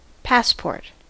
Ääntäminen
US : IPA : /ˈpæspɔɹt/